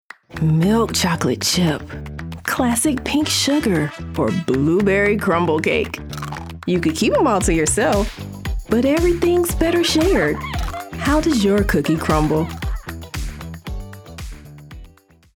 Female
Yng Adult (18-29), Adult (30-50)
My voice is warm, confident, friendly, and versatile, adapting to the needs of each project.
Television Spots
Words that describe my voice are Friendly, Relatable, Casual.